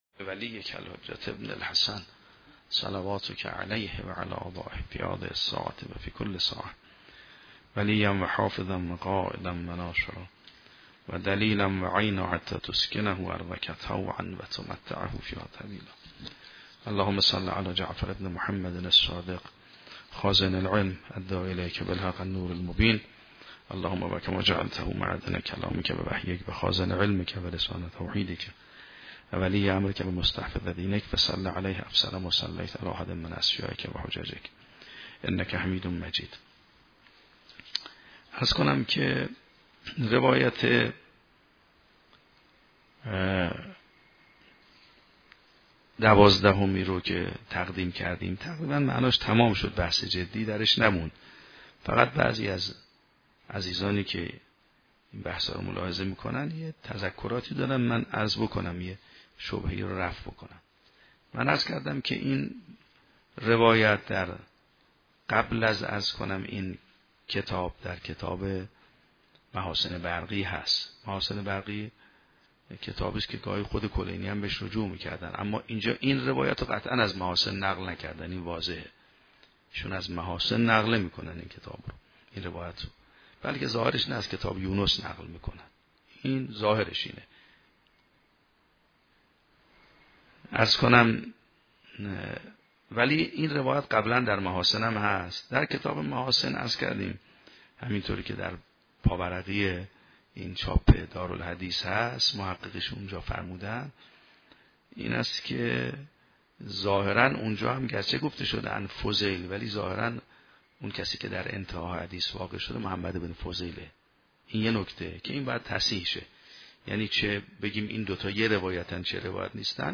شرح و بررسی کتاب الحجه کافی توسط آیت الله سید محمدمهدی میرباقری به همراه متن سخنرانی ؛ این بخش : صحیحه اسماعیل بن جابر و اقرار به مقام امام رکن دینداری عباد